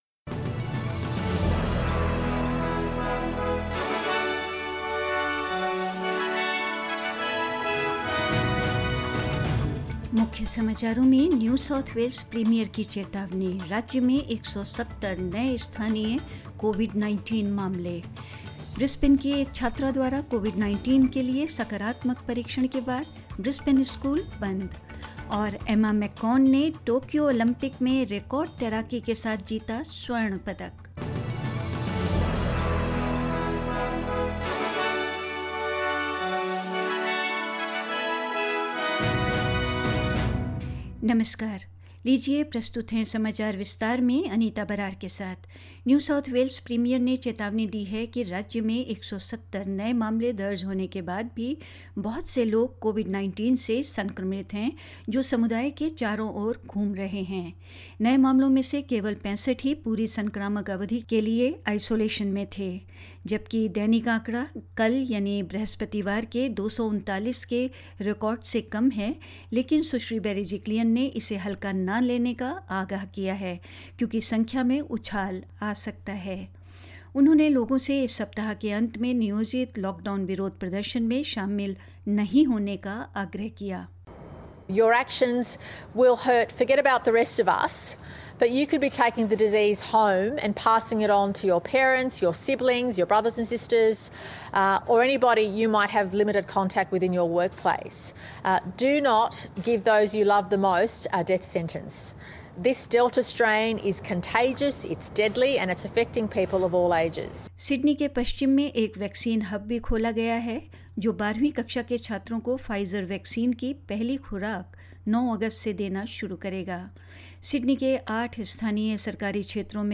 In this latest SBS Hindi News bulletin of Australia and India: A warning from the New South Wales Premier as the state records another 170 local COVID-19 cases; Alerts issued after wastewater virus detections across Melbourne; Emma McKeon lands gold with a record swim at the Tokyo Olympics; In India, Kerala registers increase in COVID -19 cases and more.